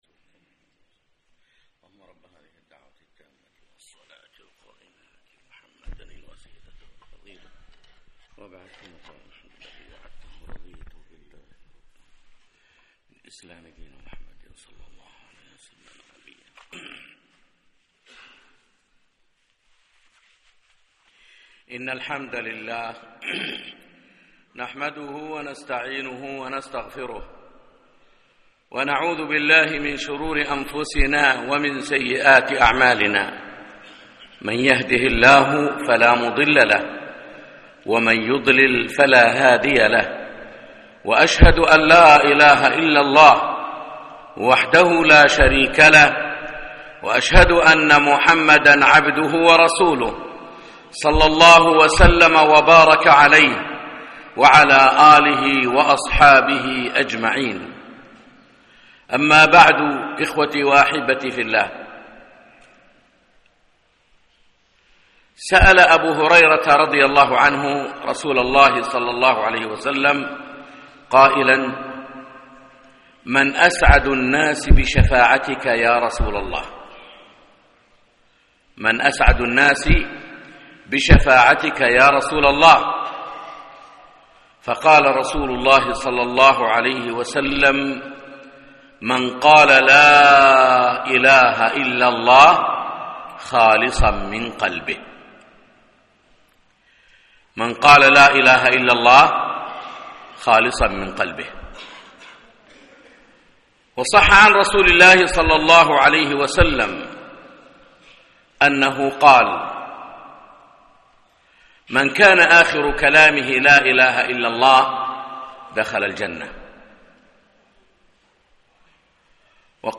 خطبة - معنى لا اله الا الله